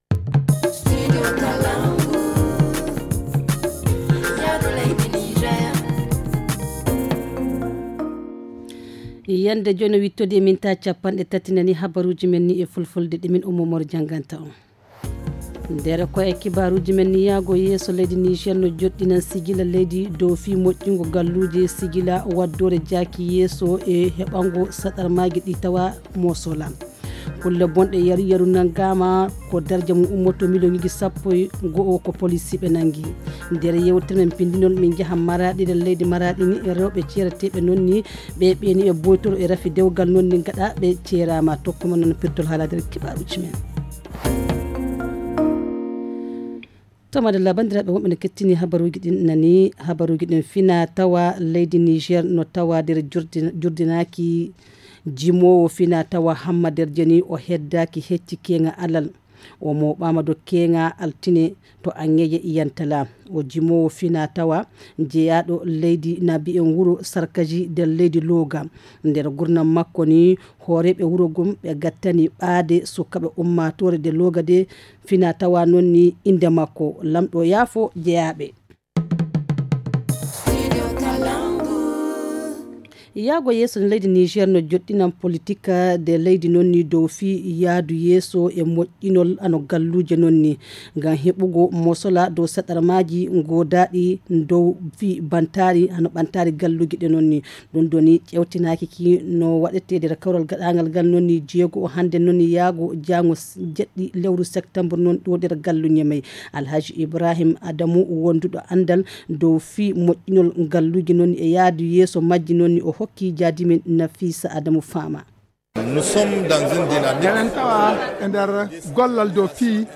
Le journal du jour 6 septembre 2022 - Studio Kalangou - Au rythme du Niger